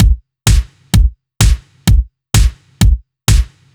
Index of /musicradar/french-house-chillout-samples/128bpm/Beats
FHC_BeatA_128-01_KickSnare.wav